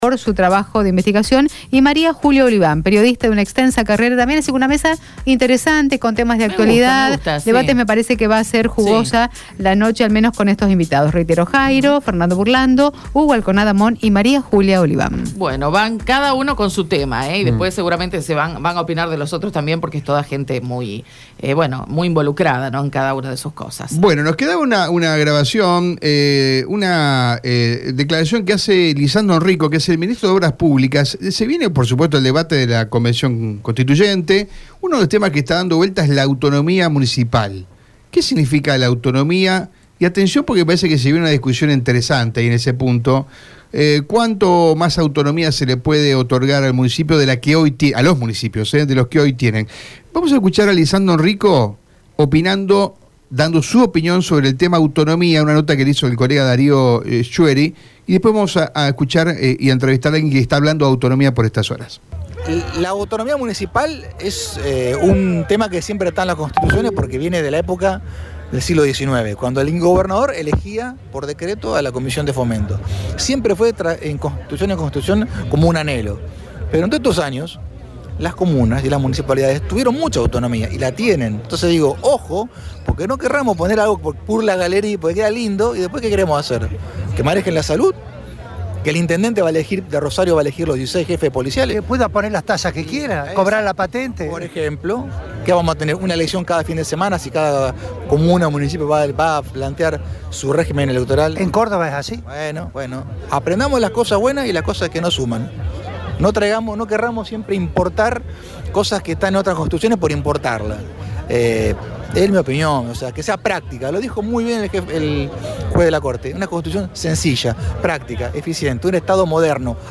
En diálogo con EME, Giuliano planteó la necesidad de superar la dependencia que tienen actualmente los gobiernos locales respecto del Ejecutivo provincial.